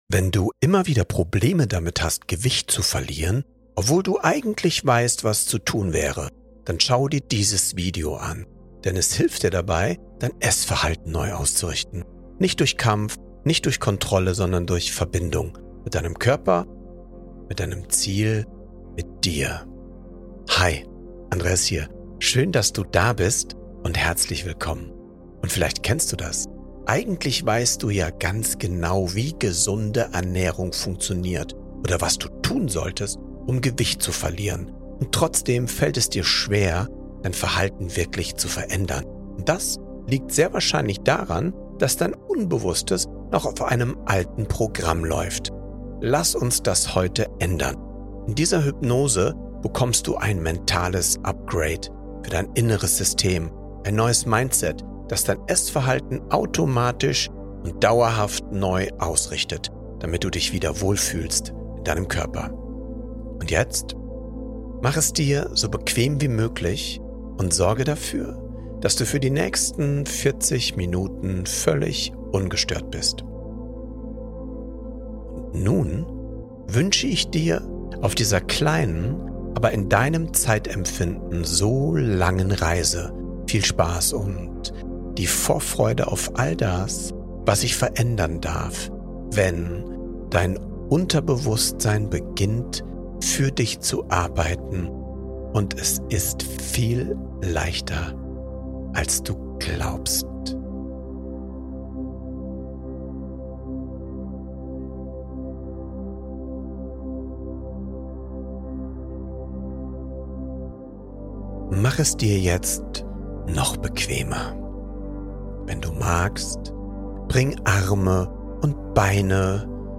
Einschlaf-Hypnose zum Abnehmen – Wunschgewicht erreichen im Schlaf ~ Happiness Mindset Podcast